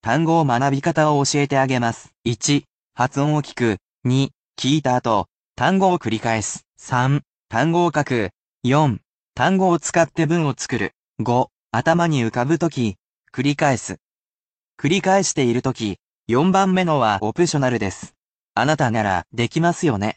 They are spoken at regular speed, so there is no need to repeat after the sentences, unless you are more advanced.